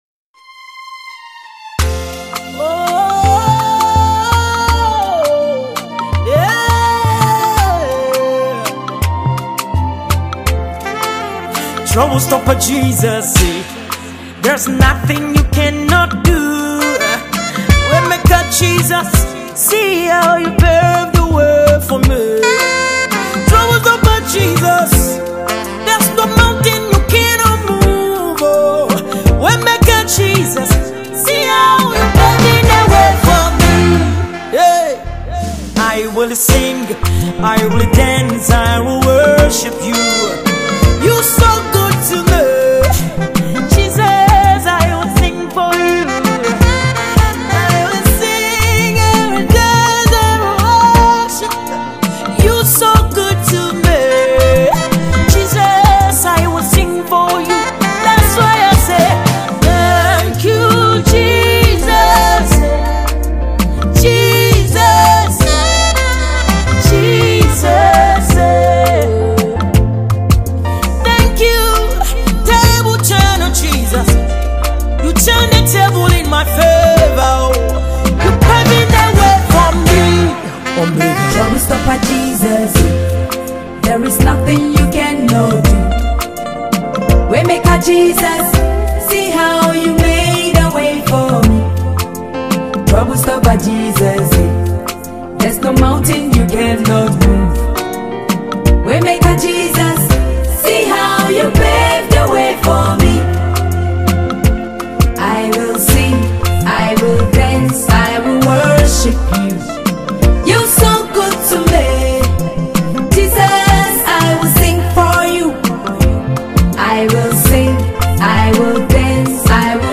Ghana Music
Ghanaian award-winning Gospel singer